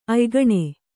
♪ aygaṇe